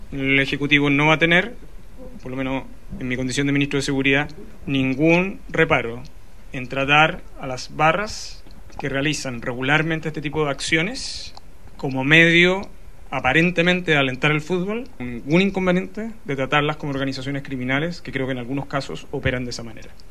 Al respecto, el ministro Cordero fue enfático en señalar que a estas barras, que se involucran en hechos de violencia, no tiene ningún inconveniente de tratarlas como organizaciones criminales.